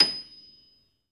53d-pno27-D6.aif